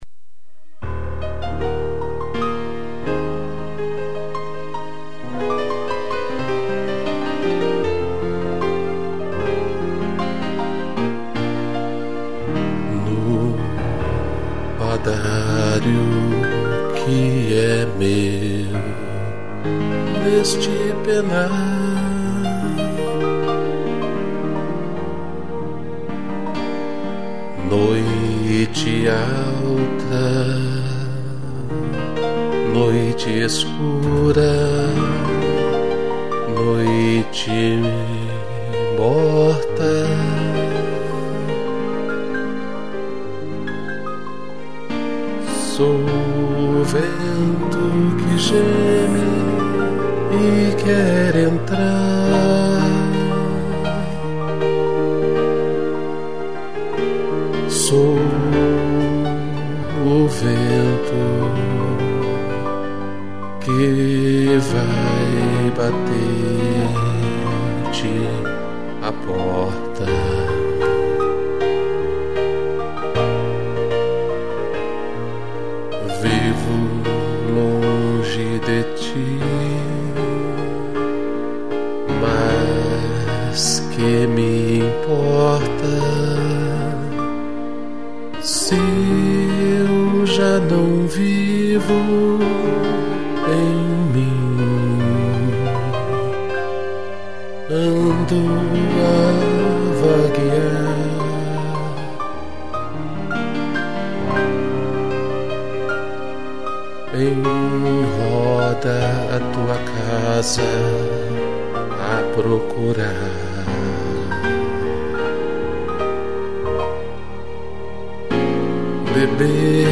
2 pianos, sax e strings
instrumental